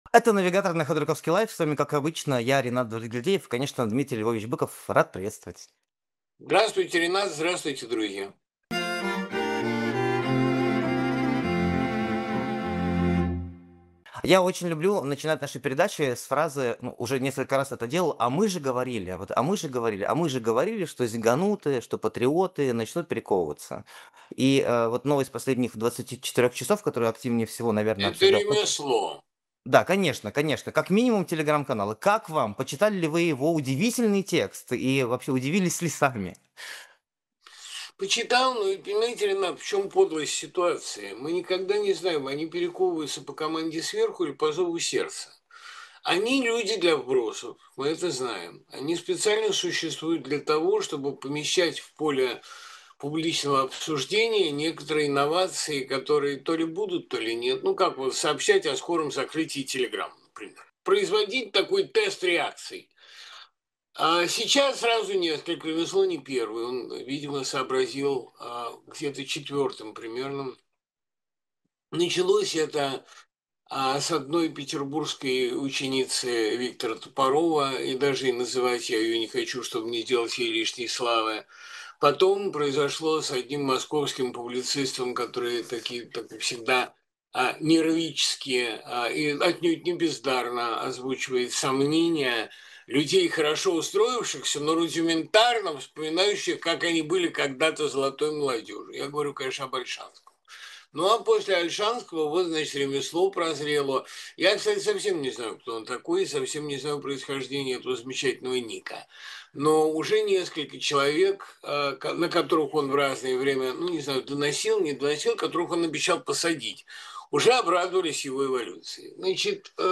Дмитрий Быков поэт, писатель, журналист